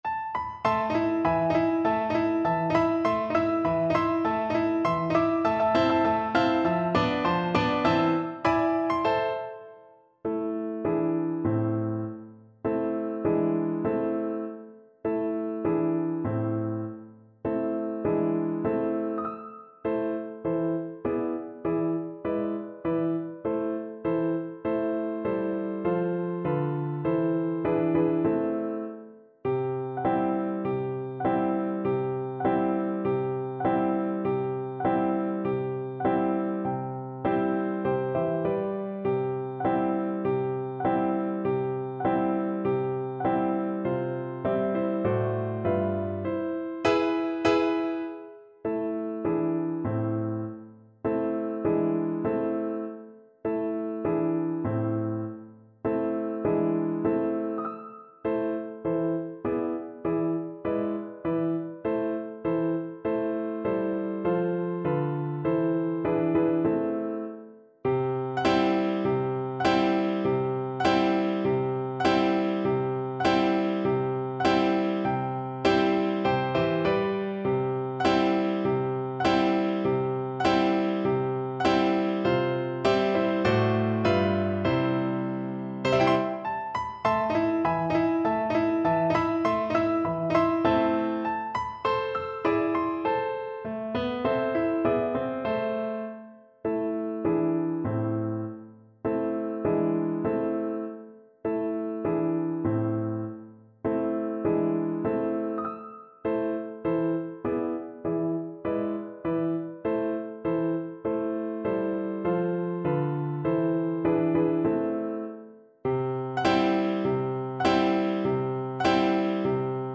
Moderato
4/4 (View more 4/4 Music)
Pop (View more Pop Flute Music)